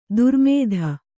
♪ durmēdha